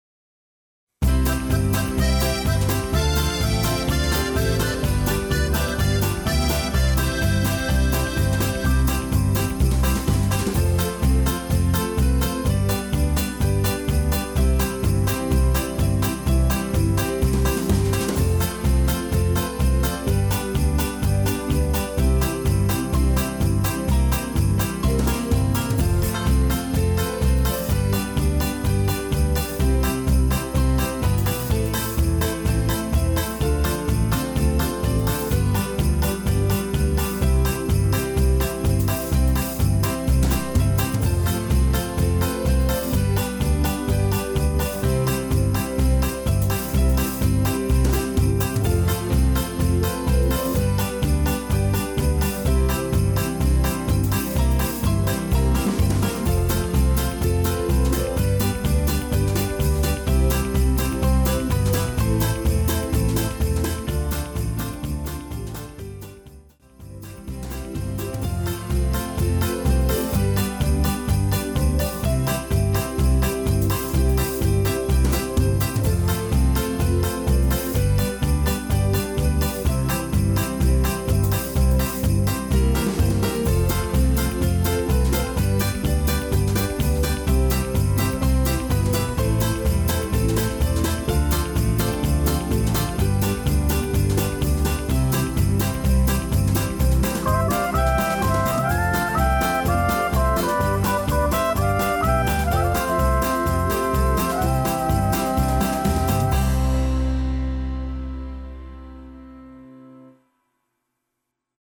Rhythm Track With Bells & Banjo